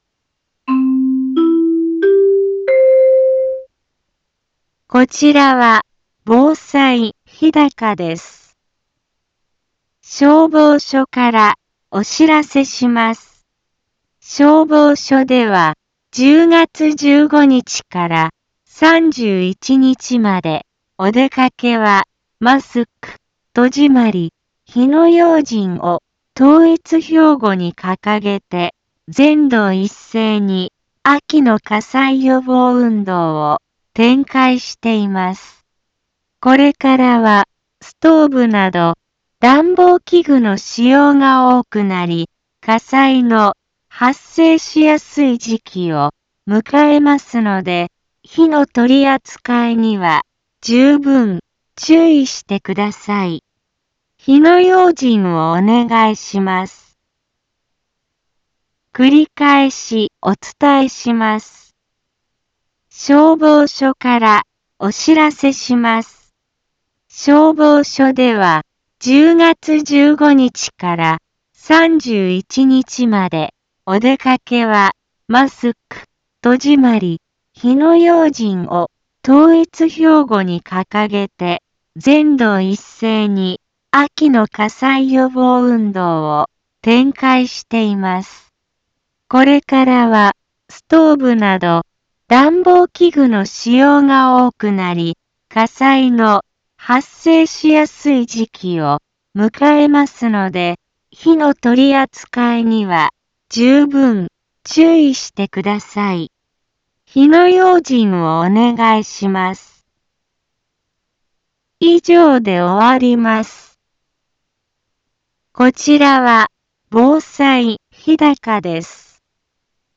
Back Home 一般放送情報 音声放送 再生 一般放送情報 登録日時：2022-10-17 10:04:22 タイトル：秋の火災予防運動に伴う予防広報について インフォメーション：こちらは防災日高です。